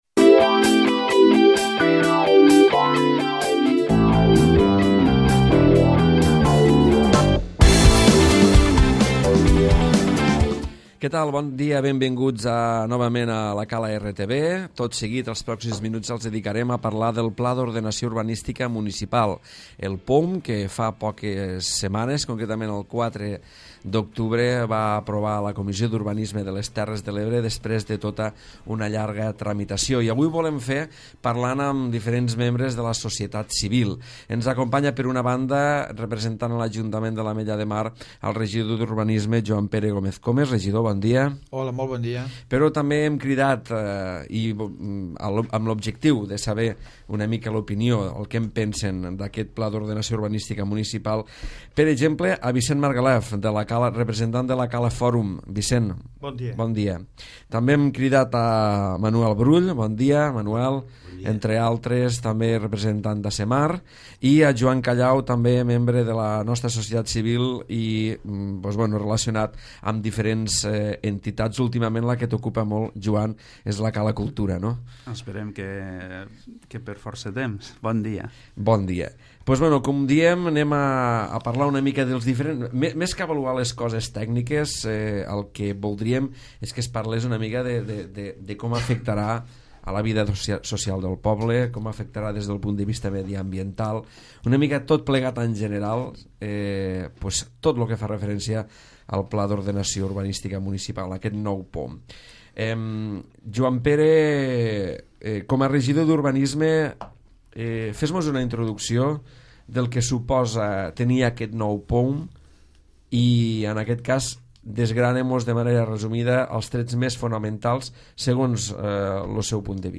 Tertúlia POUM